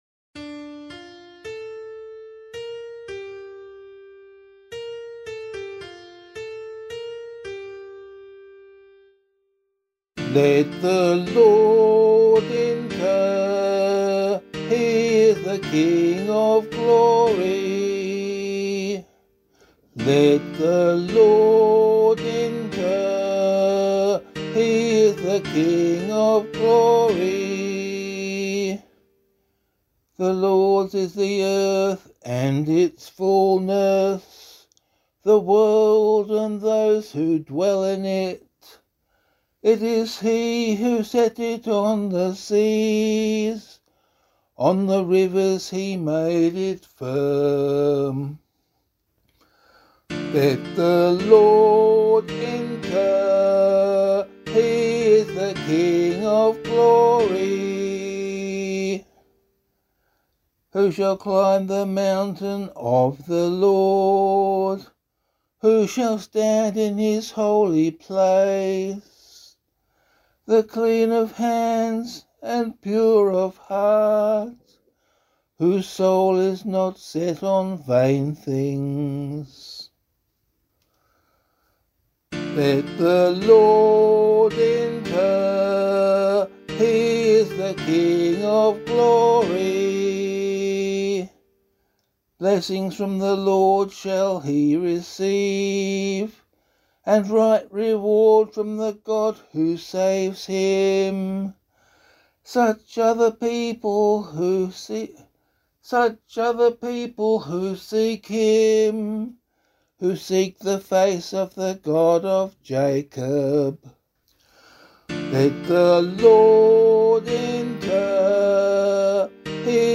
004 Advent 4 Psalm A [APC - LiturgyShare + Meinrad 4] - vocal.mp3